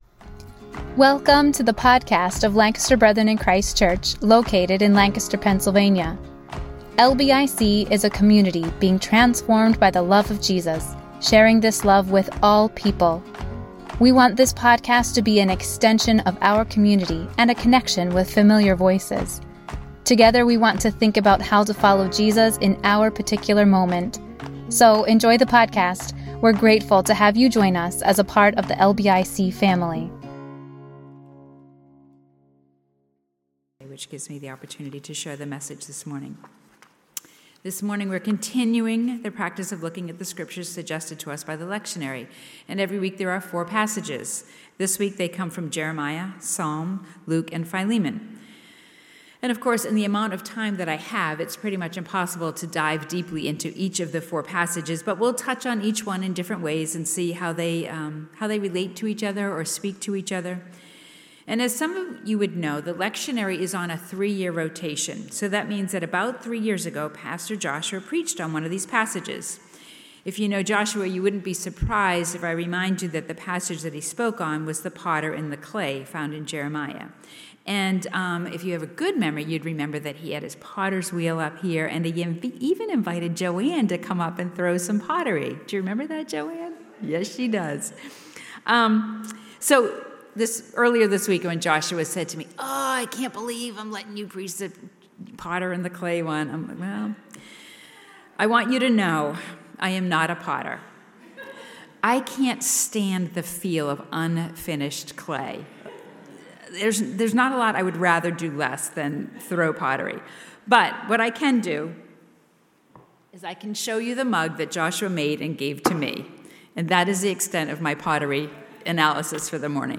23rd Sunday of Ordinary Time Service Message